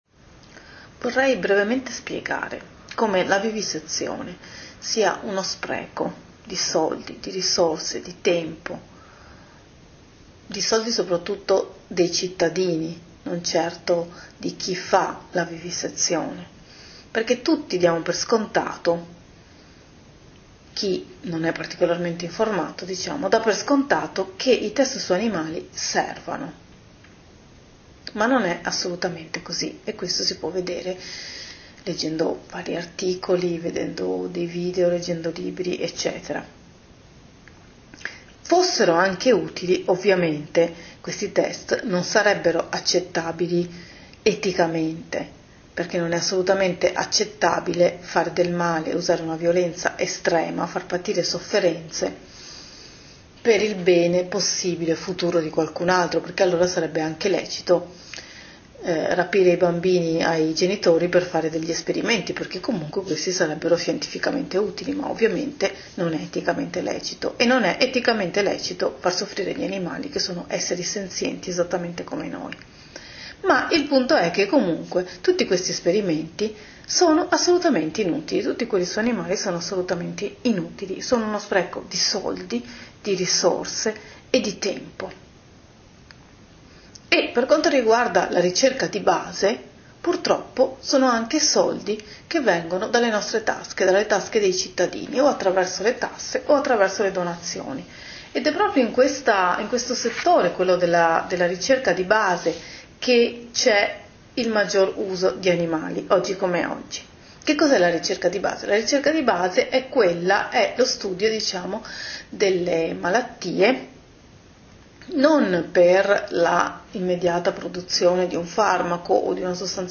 Alcuni brevi interventi in voce spiegano le basi dell'antivisezionismo: